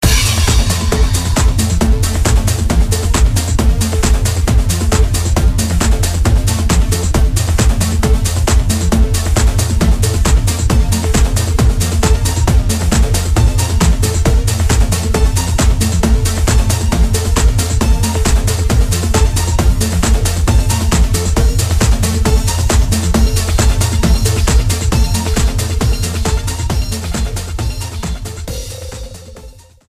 STYLE: Dance/Electronic